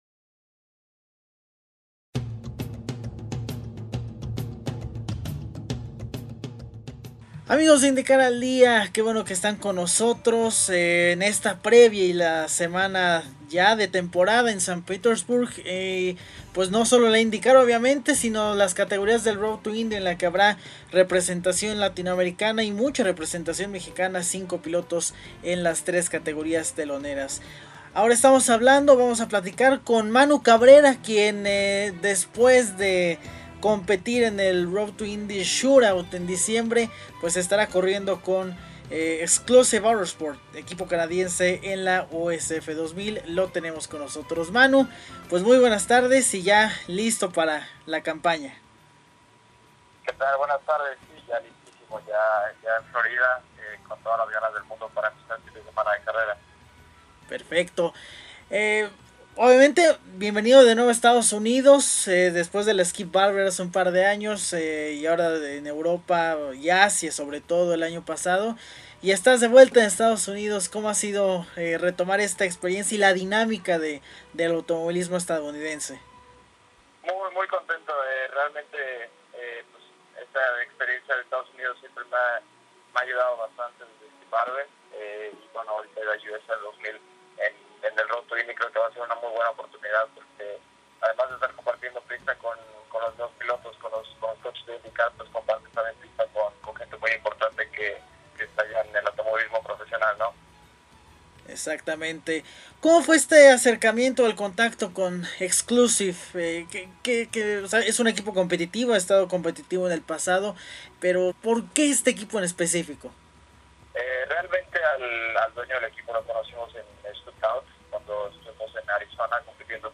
Te invitamos a escuchar la entrevista que nuestro equipo le realizó al graduado de la Formula PANAM y la Formula Masters Asia.